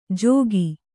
♪ jōgi